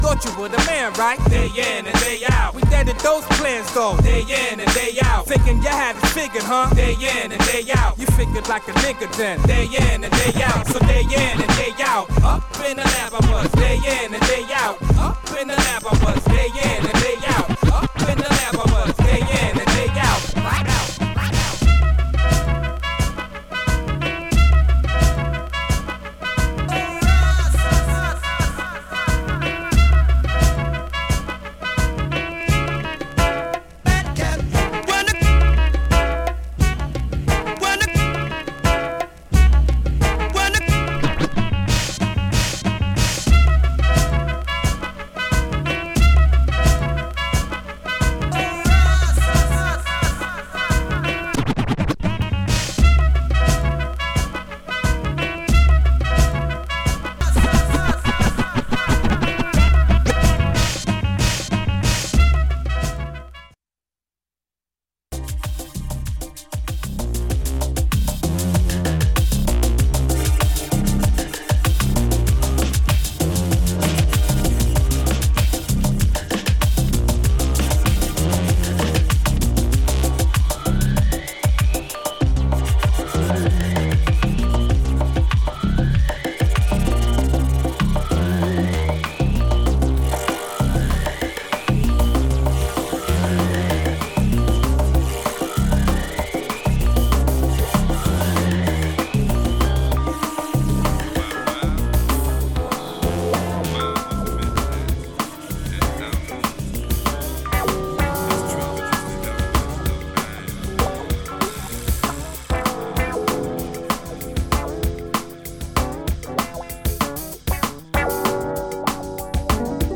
今回も仙台の自宅スタジオでライブレックを遂行。
人間味溢れるグルーヴィーでスキルフルな仕上がりになってます。
ダイジェストになります。